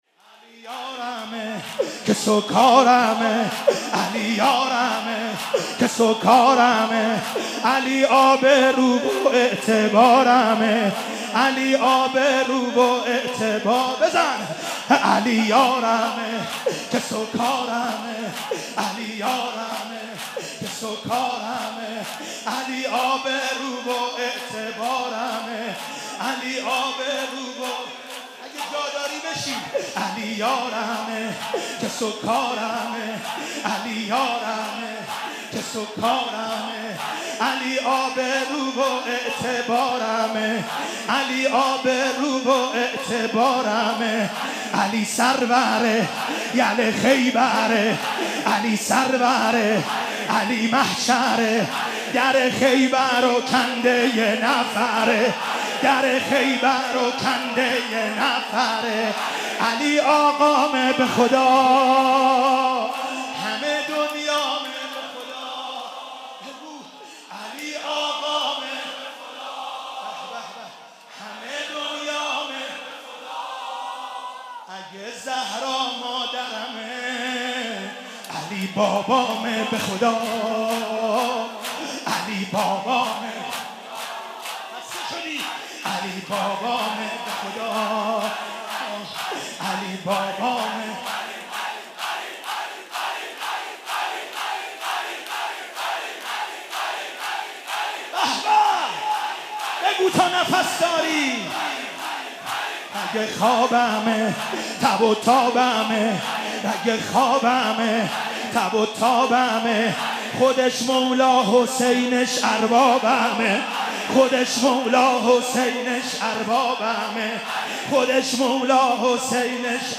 شب هفتم محرم95/هیئت رزمندگان اسلام قم